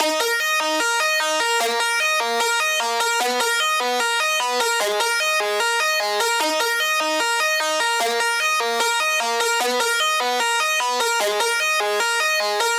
150BPM Lead 11 D#min.wav